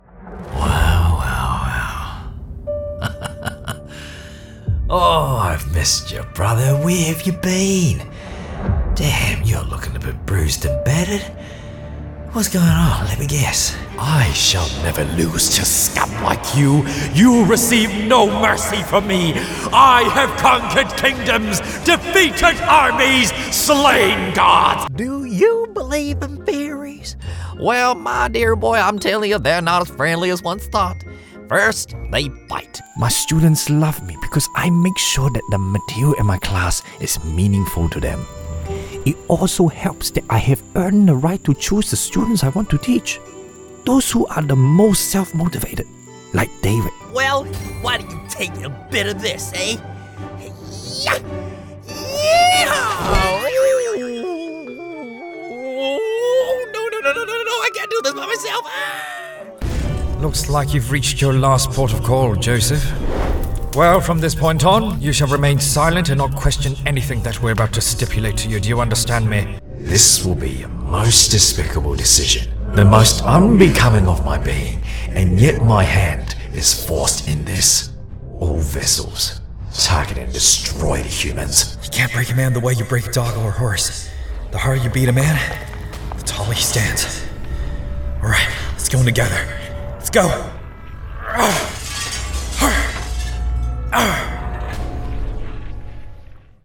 Best Male Voice Over Actors In December 2025
English (New Zealand)
Adult (30-50) | Older Sound (50+)